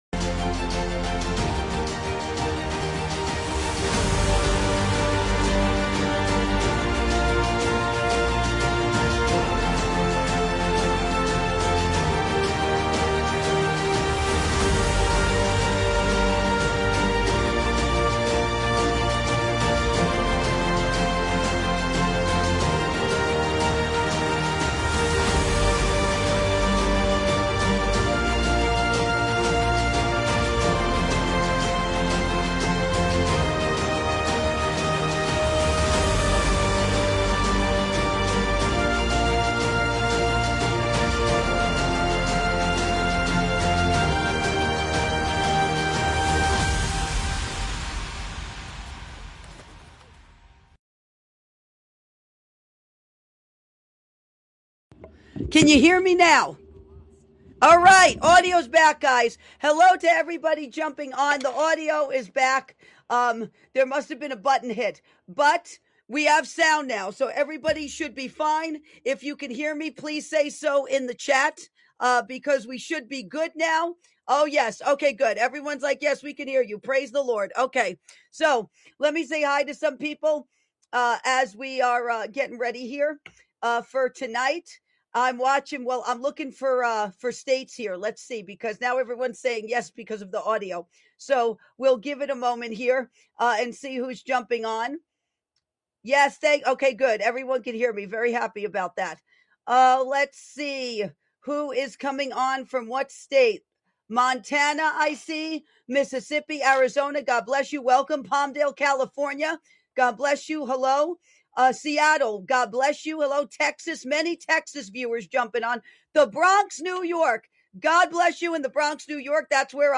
Inspirational Cinematic Music
Modern And Relaxing Background